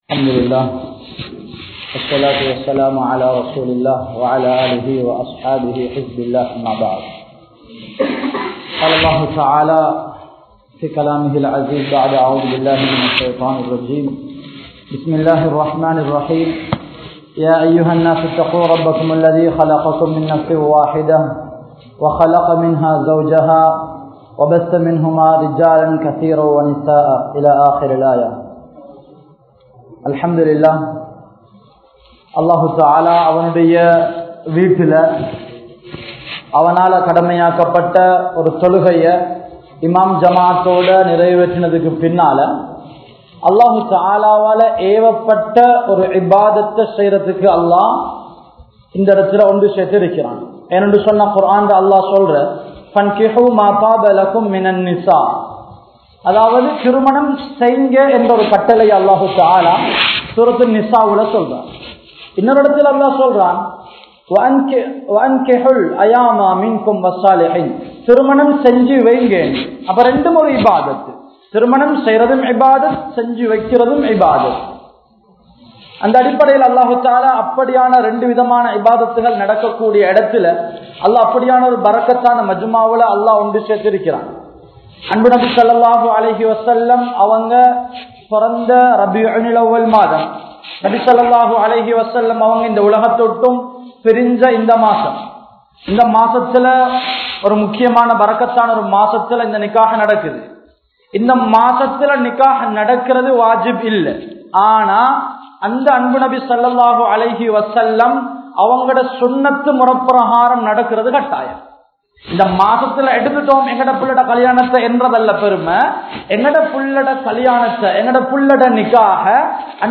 Nimmathiyaana Kudumba Vaalkai Veanduma? (நிம்மதியான குடும்ப வாழ்க்கை வேண்டுமா?) | Audio Bayans | All Ceylon Muslim Youth Community | Addalaichenai